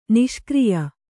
♪ niṣkriya